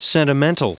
Prononciation du mot sentimental en anglais (fichier audio)
Prononciation du mot : sentimental